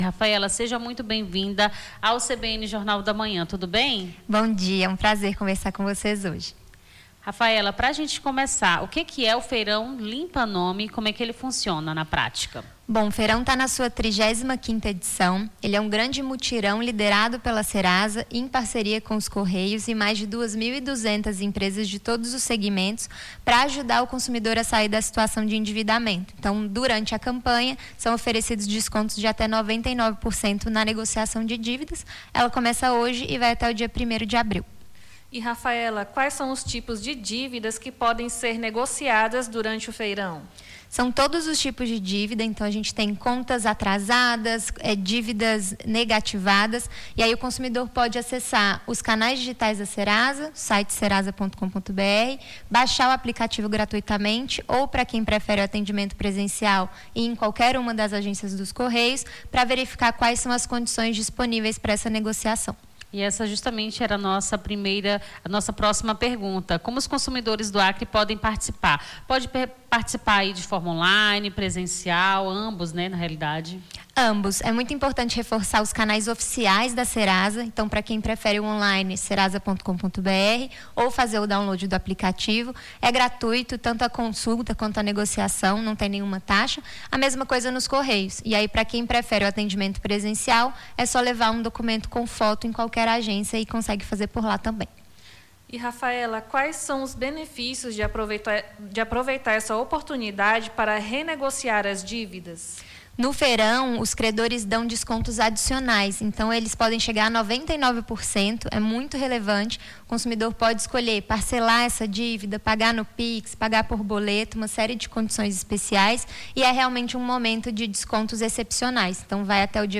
Nome do Artista - CENSURA - ENTREVISTA FEIRAO LIMPA NOME SERASA E DADOS INADIMPLENCIA - 23-02-26.mp3